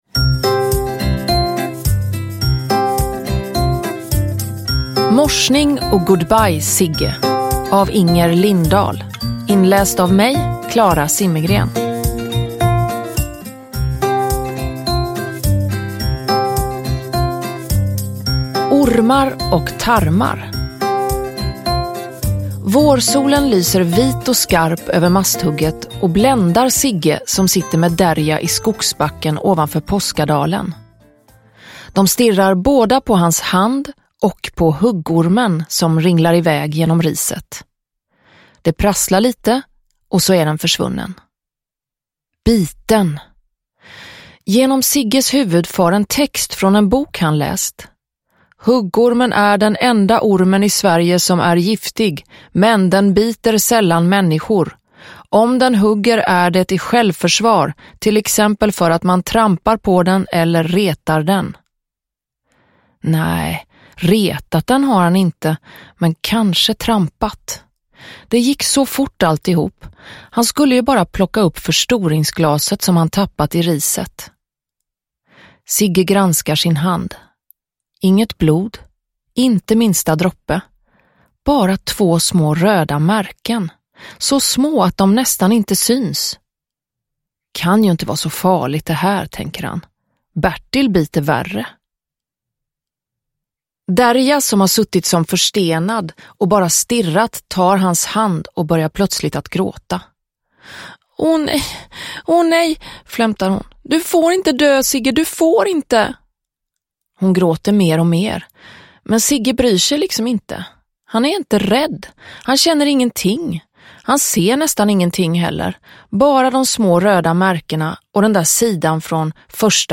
Morsning och good-bye, Zigge – Ljudbok – Laddas ner